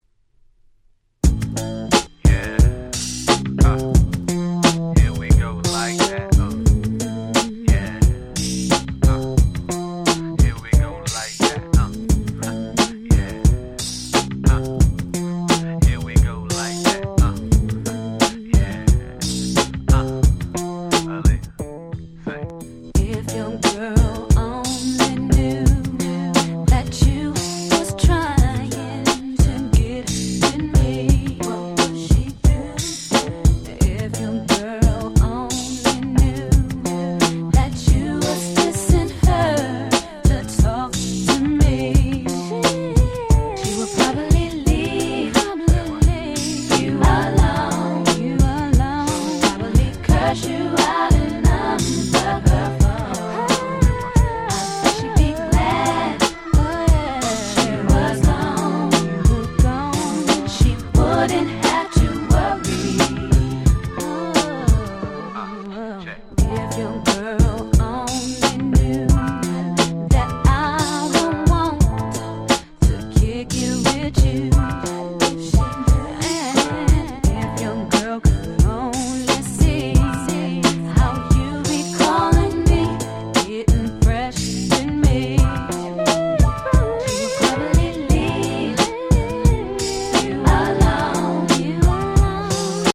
※試聴ファイルは別の盤から録音してございます。
96' Smash Hit R&B !!
Hip Hop Soul ヒップホップソウル